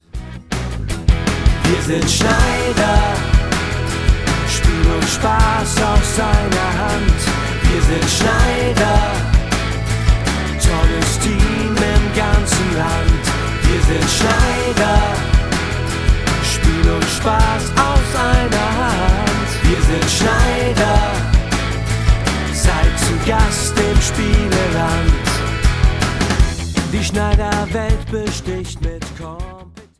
• Companysong